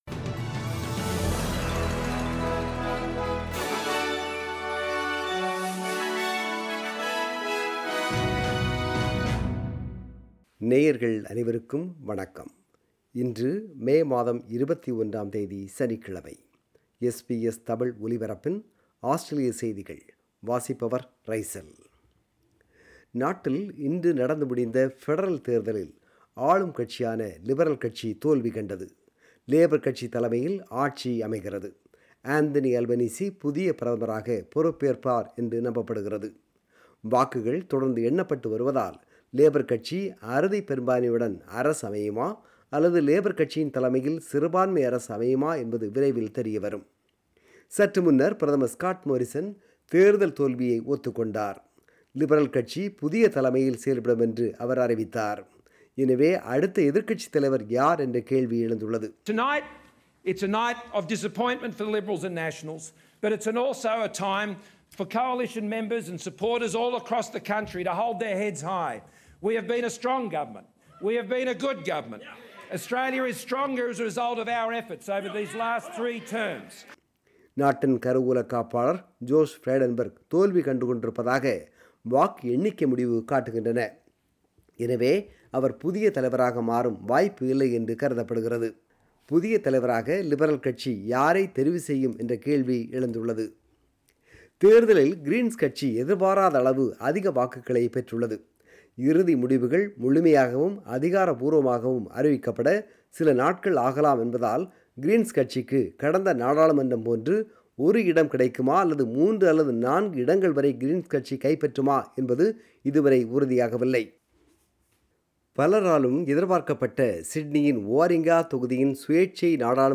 Australian News: 21 May 2022 – Saturday